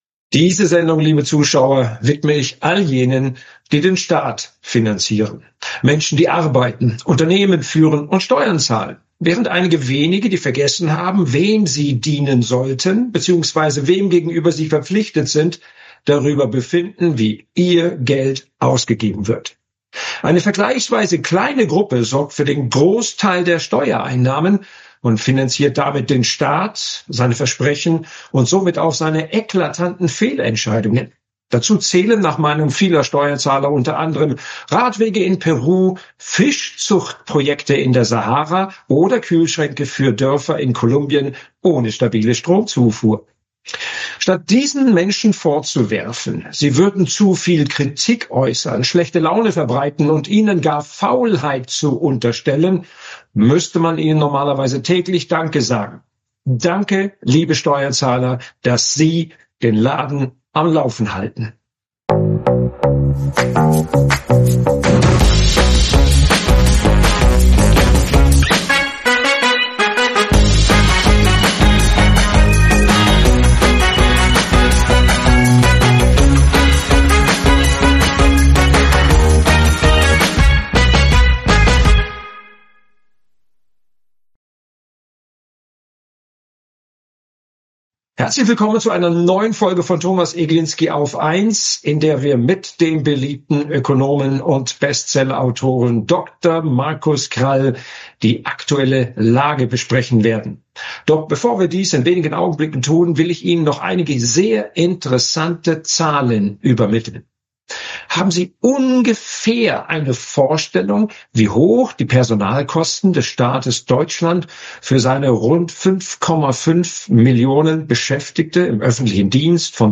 Der Ökonom und Bestsellerautor Dr. Markus Krall spricht